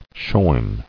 [shorn]